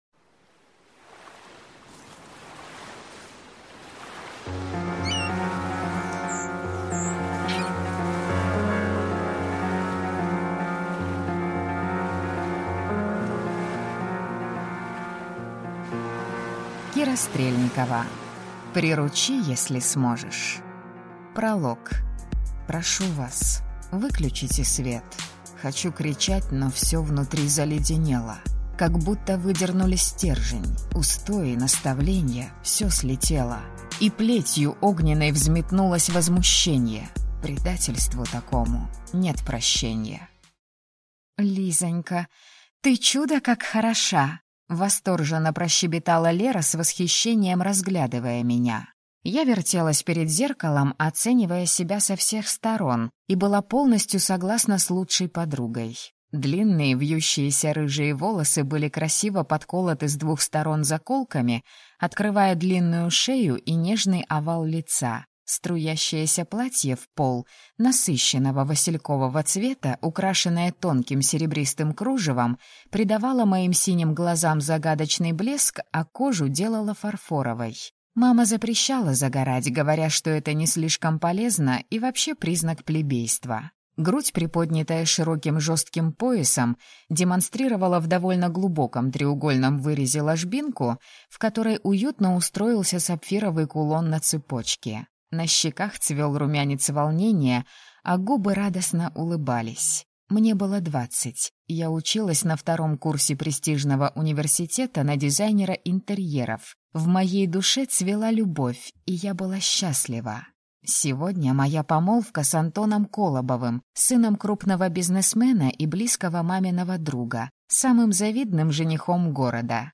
Аудиокнига Приручи, если сможешь!